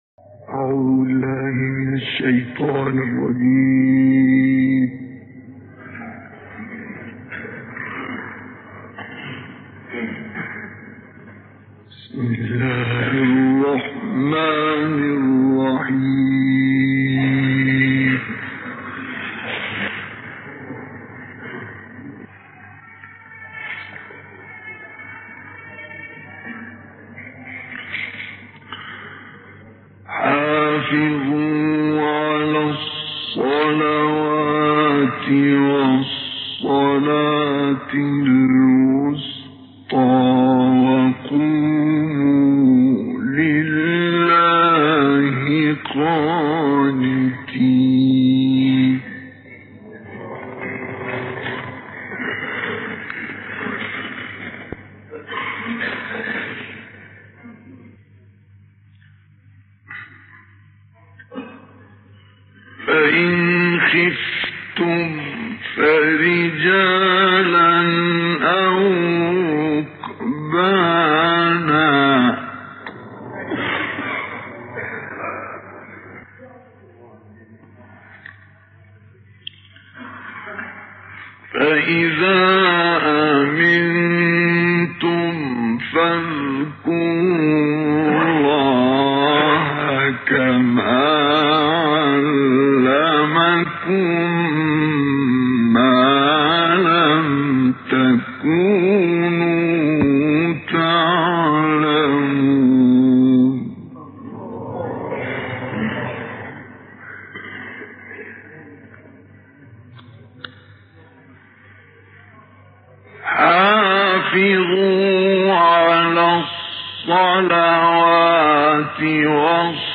تلاوتی ماندگار از استاد مصطفی اسماعیل
تهران - الکوثر : شیخ مصطفی اسماعیل این تلاوت زیبا را که آیات 238 تا 252 سوره بقره و سوره های طارق و انشراح را شامل می شود اواخر دهه 50 در سوریه اجرا کرد.